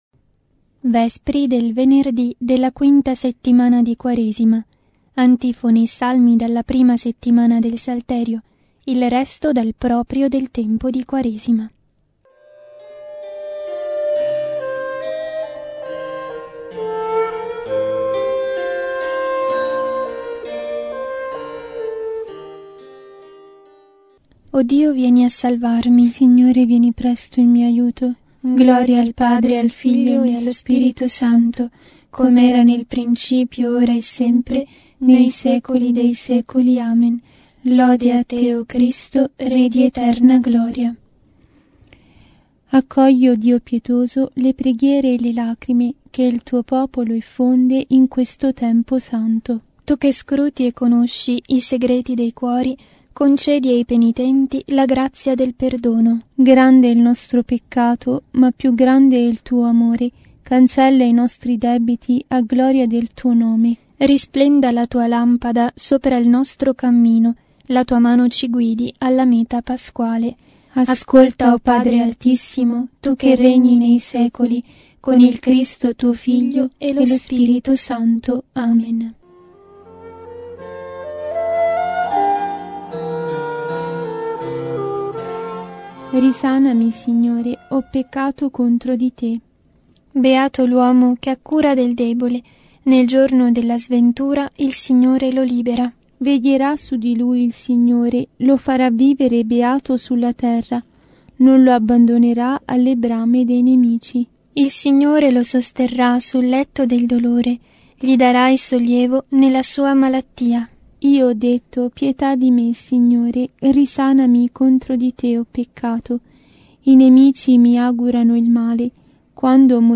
Vespri – Venerdì – 5° settimana di Quaresima